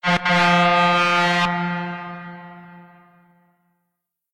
Truck Horn Double Medium Length
Big Big-Truck Double Hit-Horn Hitting Horn Multiple Press-Horn sound effect free sound royalty free Memes